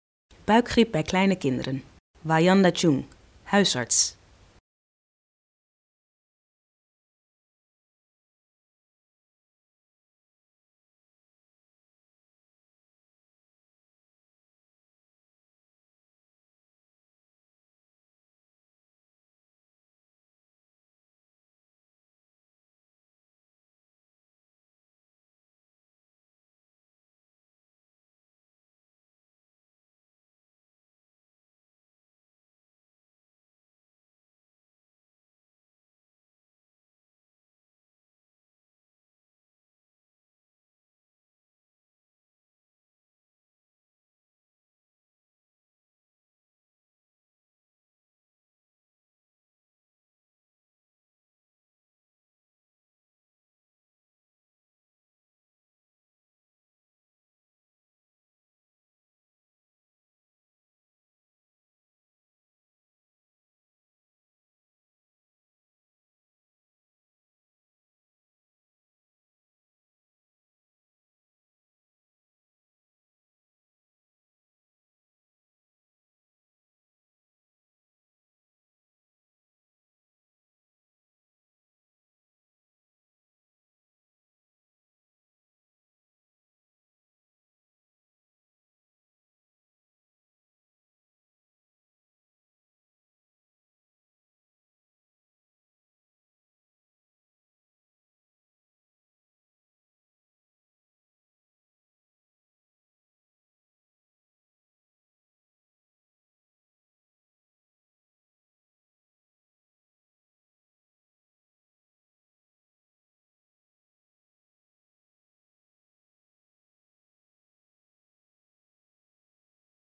In deze video wordt u door een huisarts uitgelegd wat de buikgriep bij kinderen is, hoe u het kan voorkomen en wat u moet doen als u deze ziekte heeft.